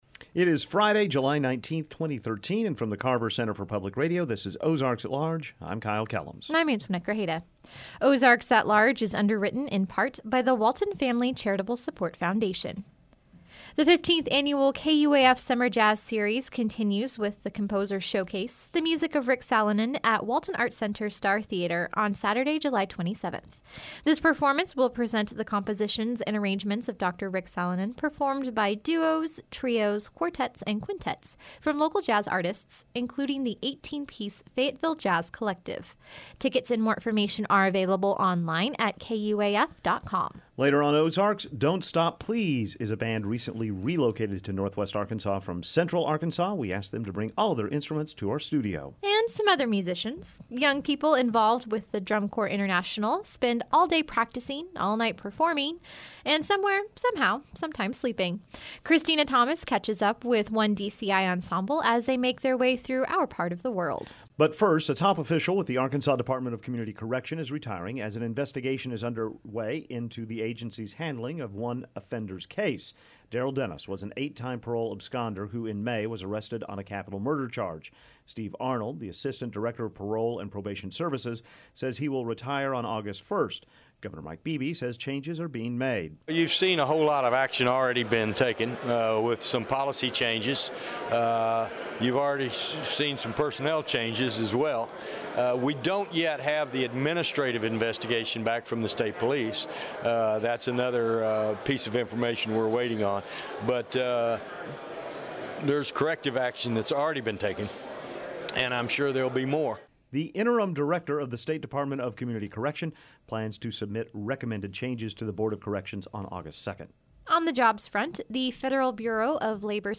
We asked them to bring all of their instruments to our studio.